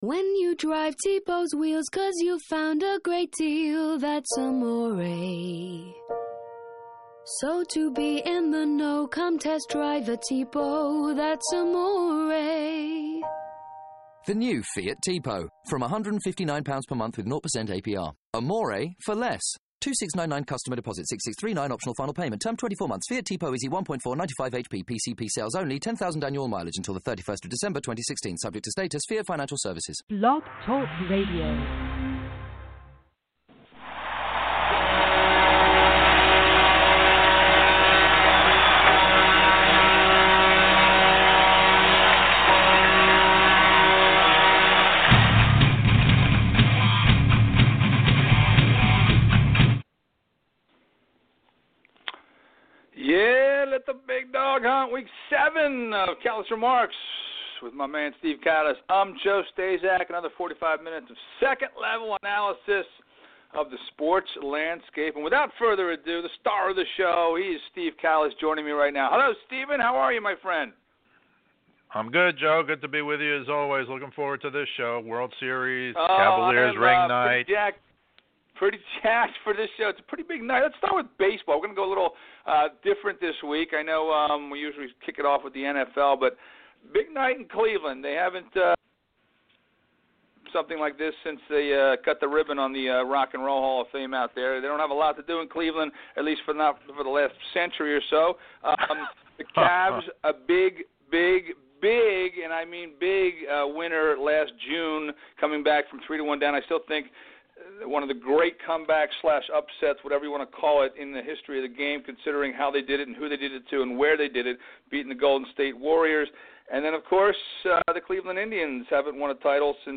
2nd level analysis of the sports landscape call-in sho